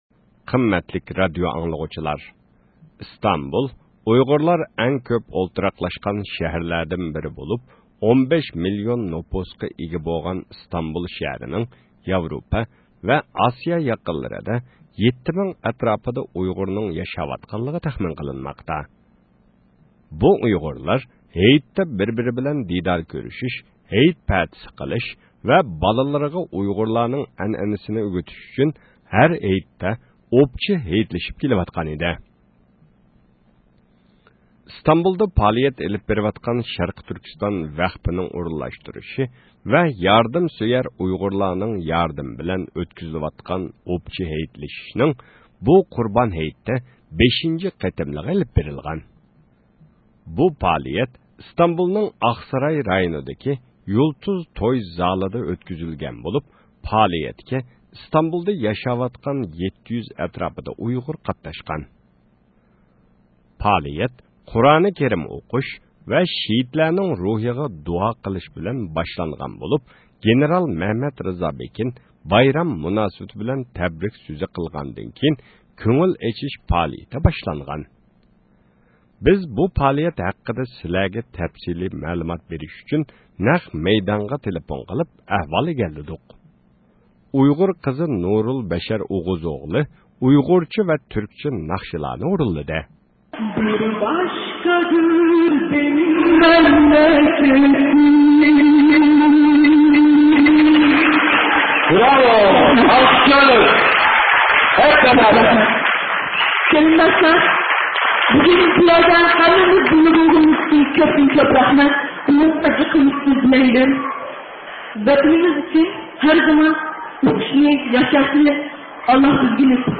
بىز بۇ پائالىيەت ھەققىدە سىلەرگە تەپسىلى مەلۇمات بېرىش ئۈچۈن نەق مەيدانغا تېلېفون قىلىپ ئەھۋال ئىگىلىدۇق.